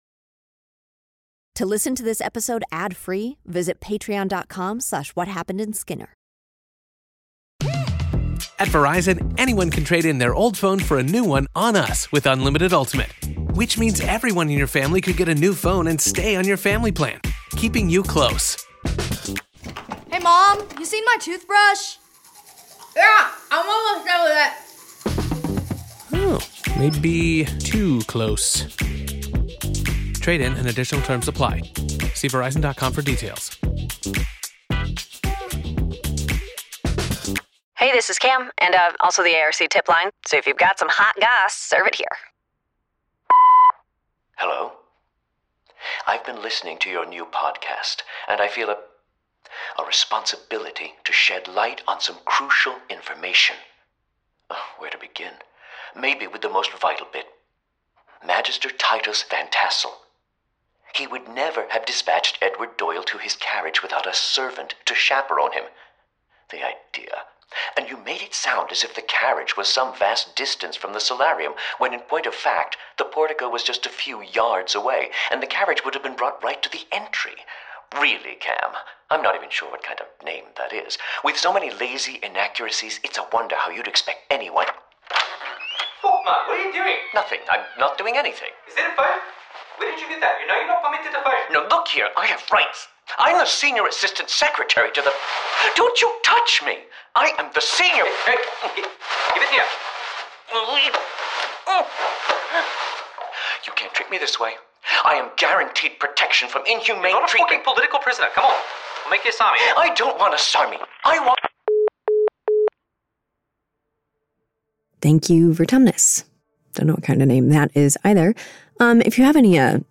… continue reading 23 episodes # Audio Drama # Mazama Entertainment # Sci-Fi / Fantasy Stories